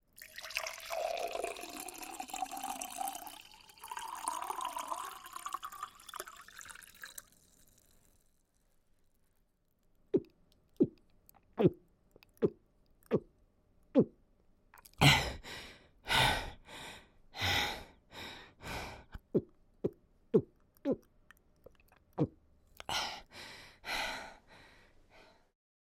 浇灌饮料
描述：液体倒入杯中
标签： 强麦 浇筑 液体 饮料 家庭录音
声道立体声